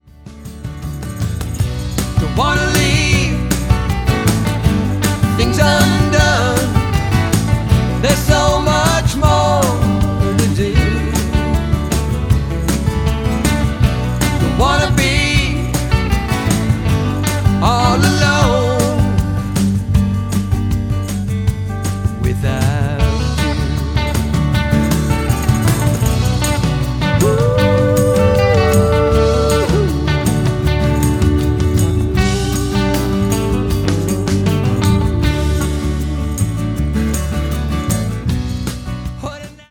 Folk/West Coast collective
heartfelt Americana with breezy West Coast vibes
vocals
electric guitar
bass
vintage tones